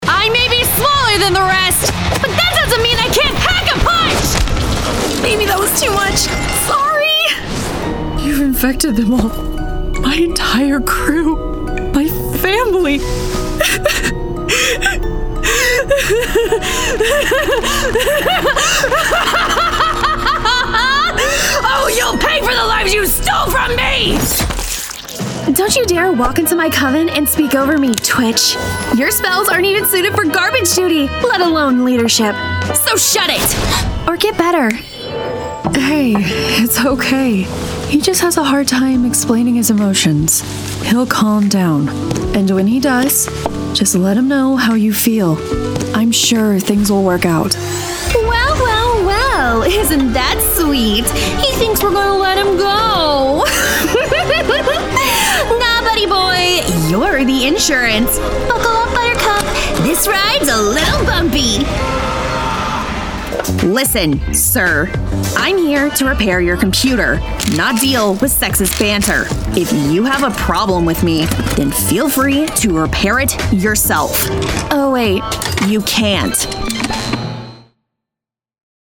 Young Adult
Has Own Studio
australian | character
southern us | natural
standard us | natural
ANIMATION 🎬